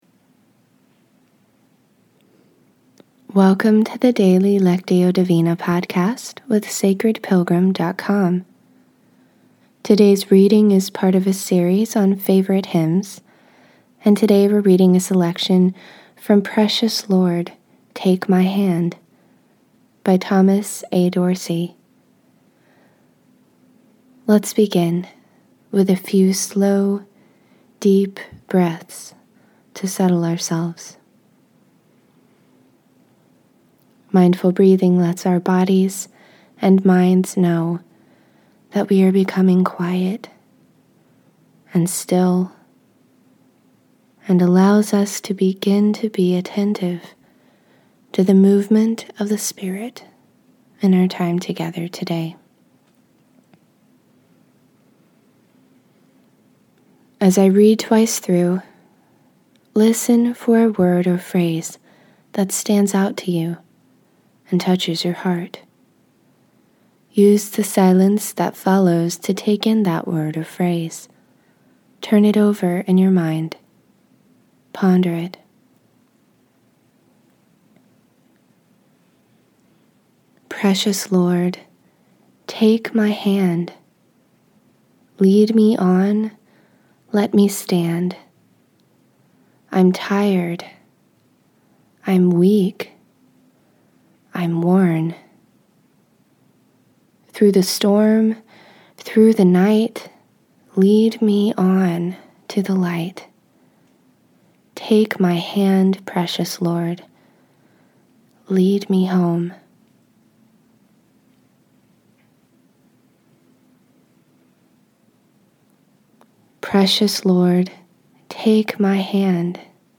In this episode of the guided lectio divina podcast, we’re using a selection from “Precious Lord, Take My Hand” by Thomas A. Dorsey.